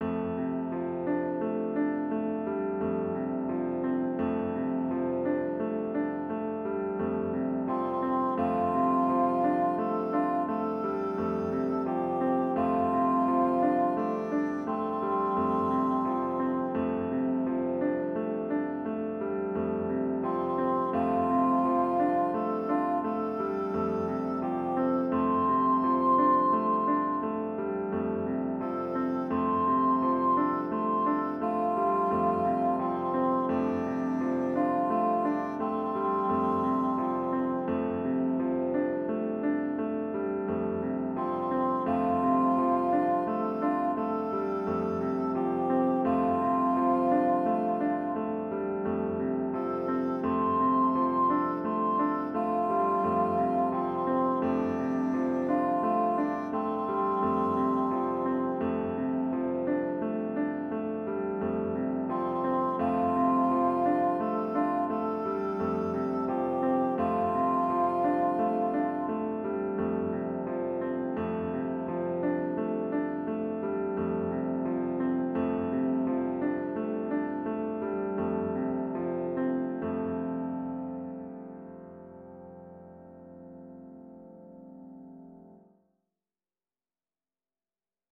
Voicing/Instrumentation: Choir Unison , Piano Solo
Simplified Arrangement/Easy Play